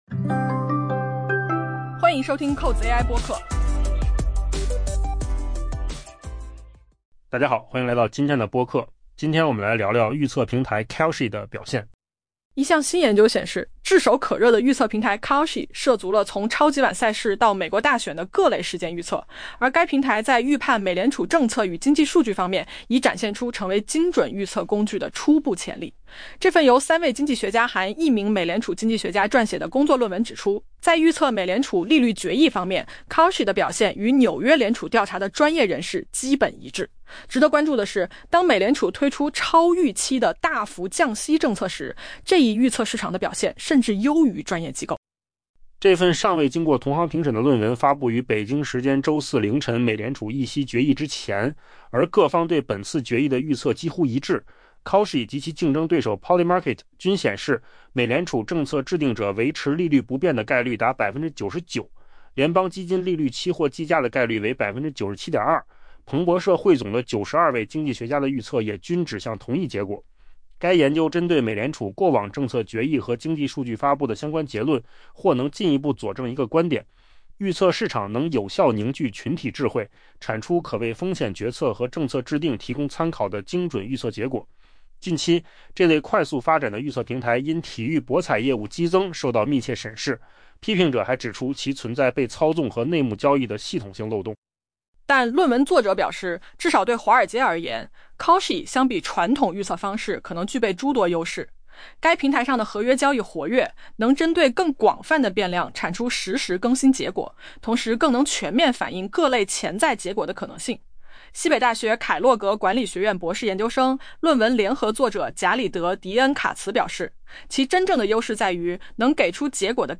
AI 播客：换个方式听新闻 下载 mp3 音频由扣子空间生成 一项新研究显示，炙手可热的预测平台 Kalshi 涉足了从超级碗赛事到美国大选的各类事件预测，而该平台在预判美联储政策与经济数据方面，已展现出成为精准预测工具的初步潜力。